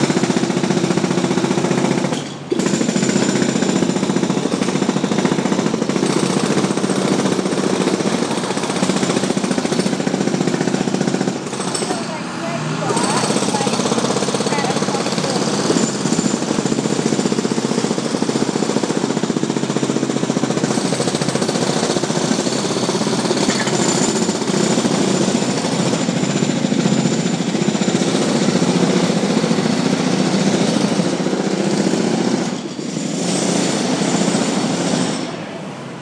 London on a Monday
Noise chaos traffic I want to go home